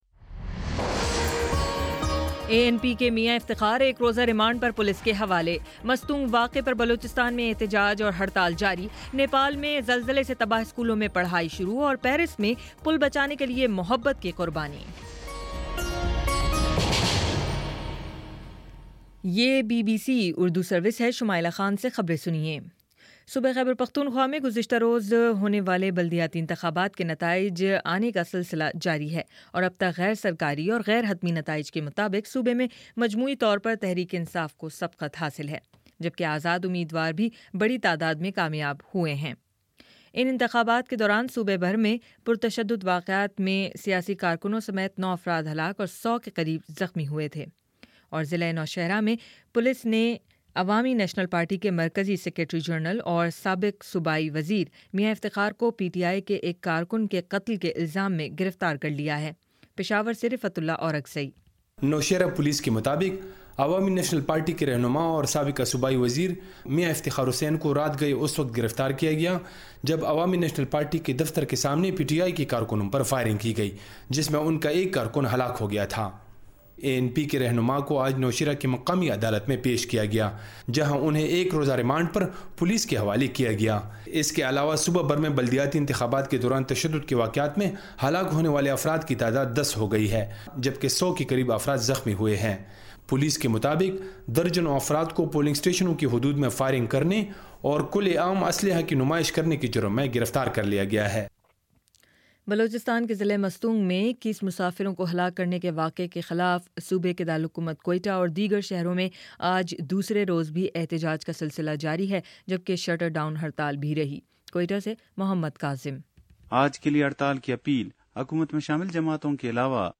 مئی 31: شام چھ بجے کا نیوز بُلیٹن